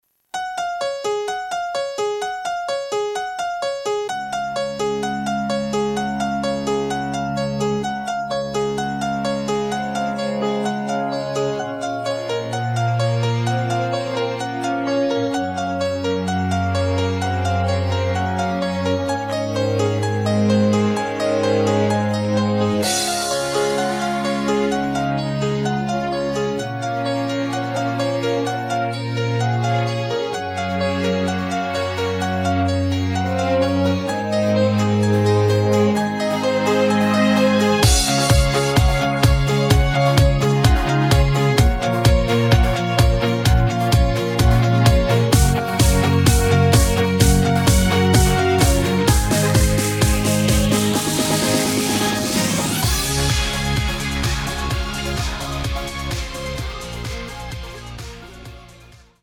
음정 원키 4:40
장르 가요 구분 Voice MR